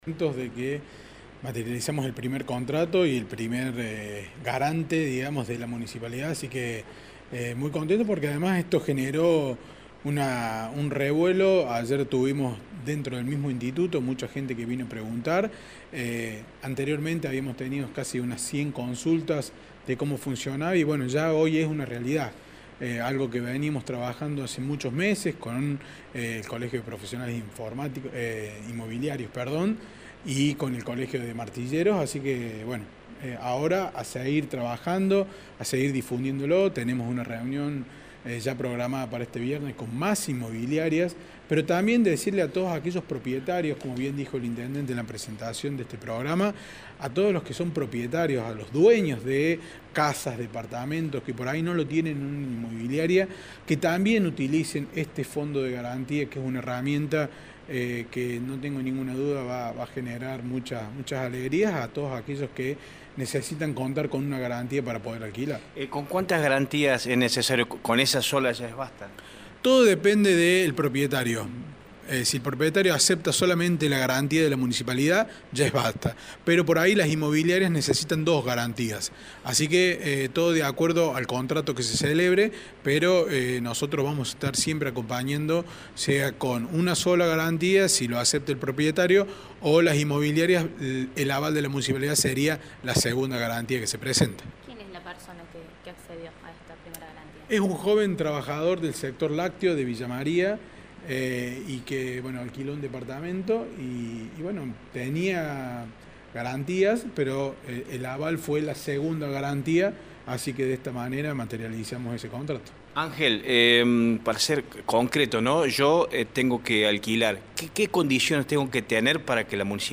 El presidente del Instituto Municipal de la Vivienda, Ángel Quaglia, habló sobre el primer contrato firmado y además, reitero los requisitos para acceder a este programa.